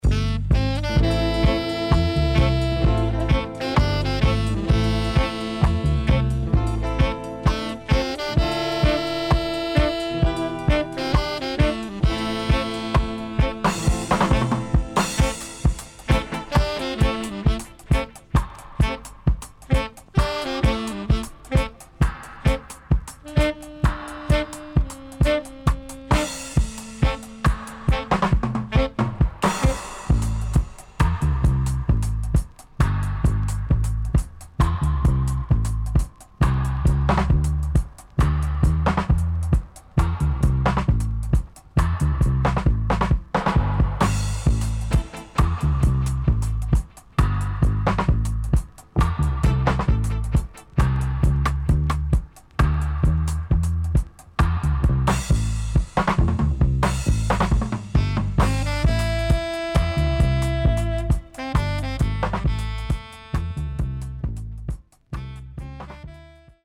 HOME > Back Order [VINTAGE 7inch]  >  KILLER & DEEP